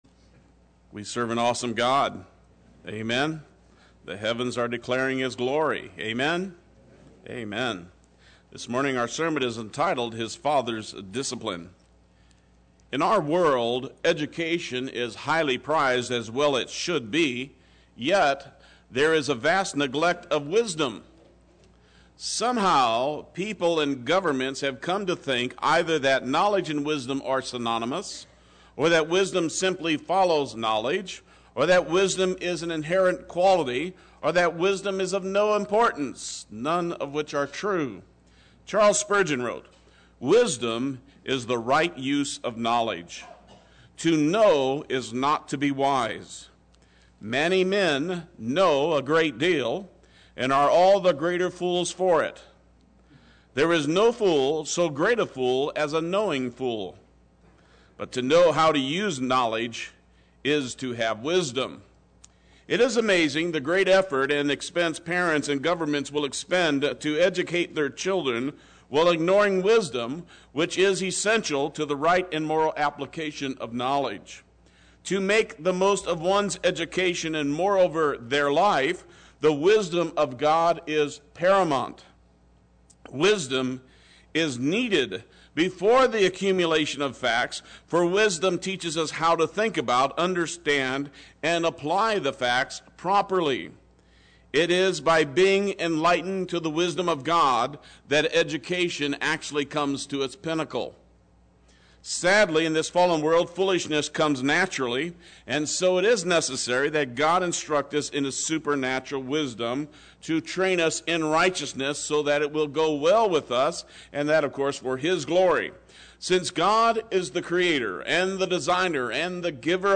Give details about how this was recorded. “His Father’s Discipline” Sunday Worship